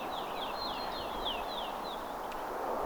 vähän erikoinen talitiaisen säe?
ilm_tuollainen_talitiaisen_sae.mp3